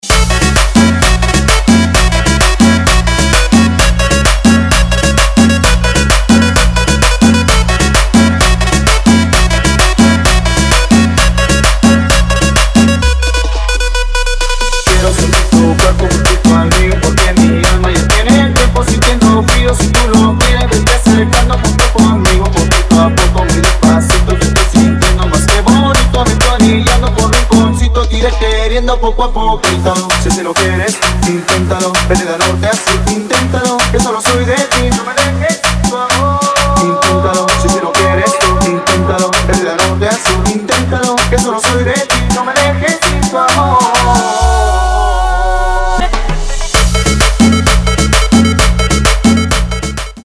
Dive into the pulsating rhythms and energy of Latin music